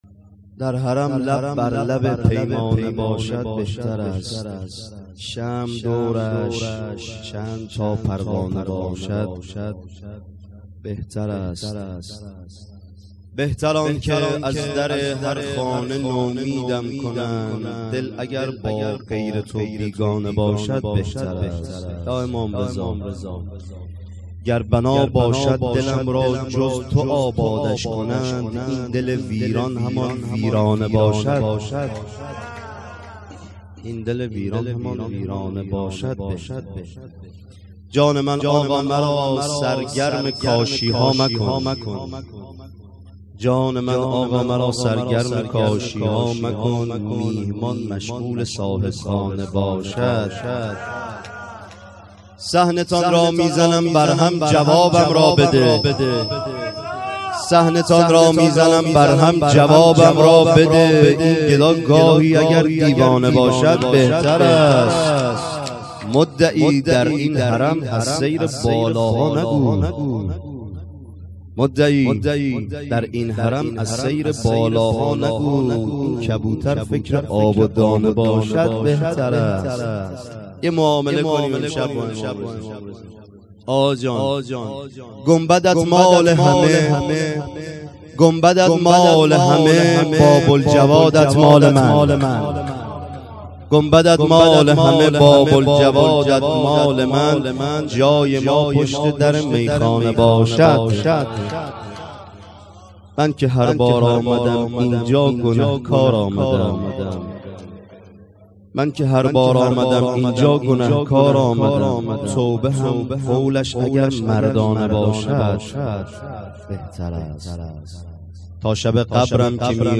شعر خوانی: درحرم لب بر لب پیمانه باشد بهتر است